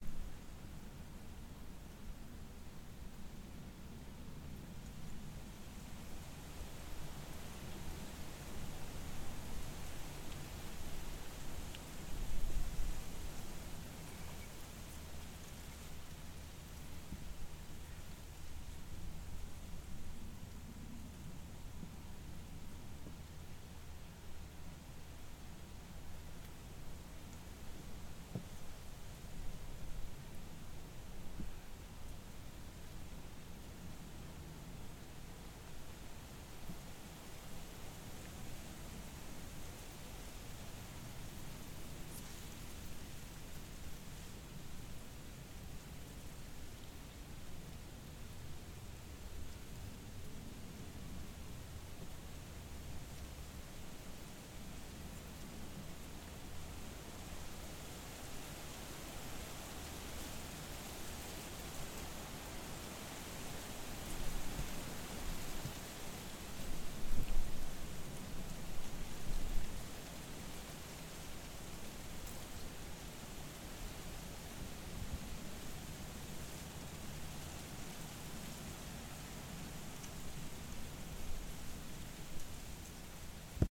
Windy Day.ogg